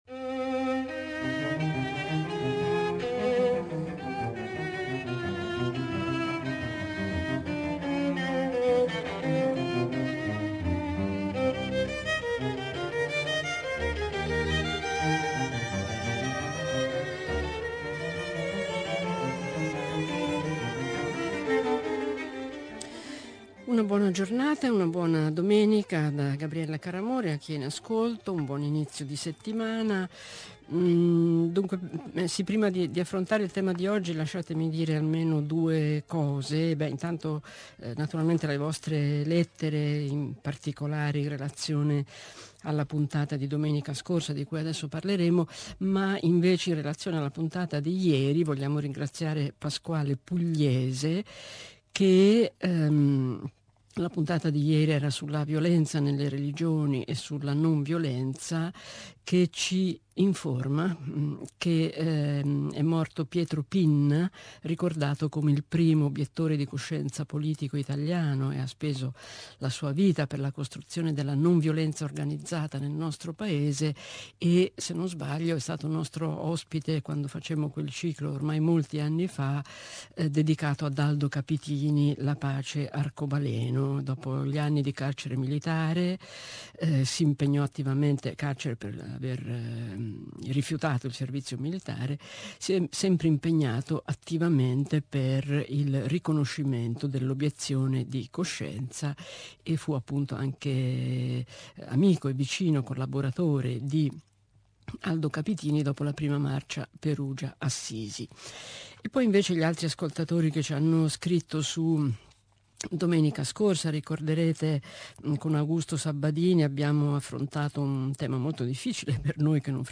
da "uomini e profeti" trasmissione di radio tre.